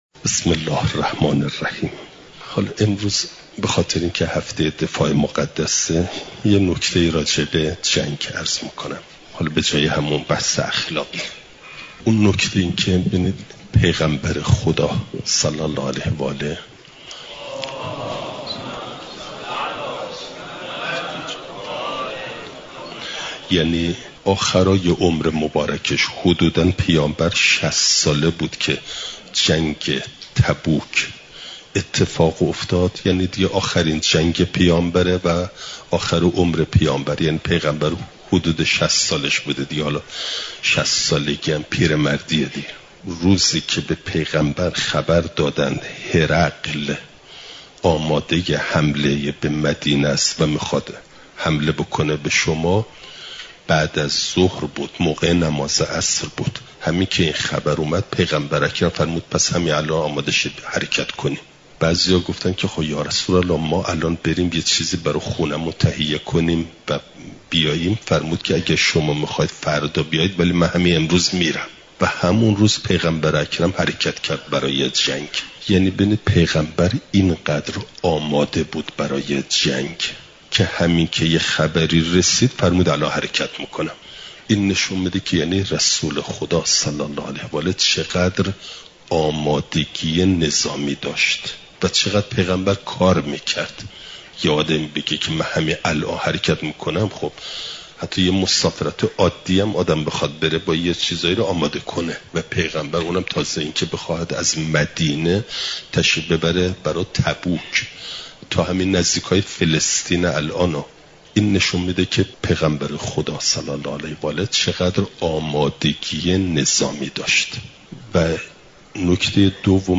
چهارشنبه ۲ مهرماه ۱۴۰۴، حرم مطهر حضرت معصومه سلام ﷲ علیها